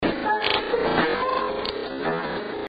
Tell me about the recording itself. Also, we have some cleaned up versions of our EVPs… a couple new ones! These are cleaned up for ya.